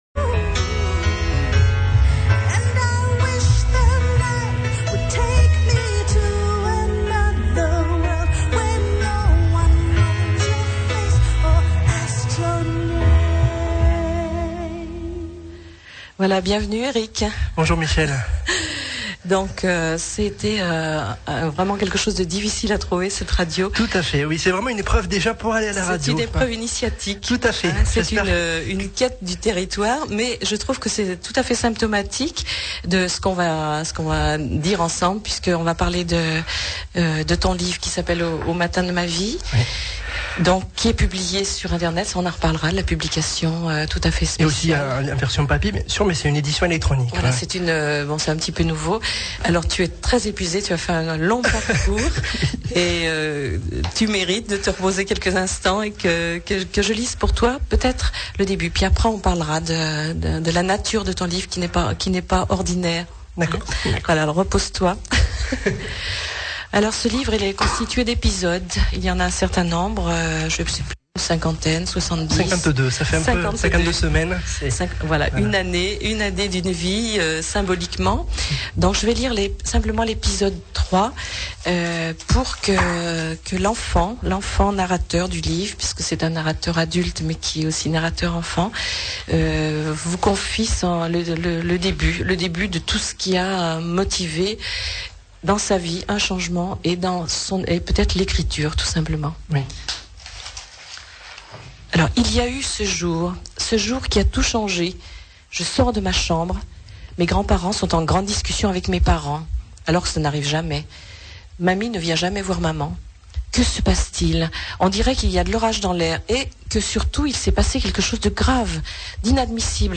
Radio Pluriel, le 19 septembre 2005 première interview